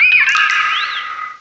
pokeemerald / sound / direct_sound_samples / cries / manaphy.aif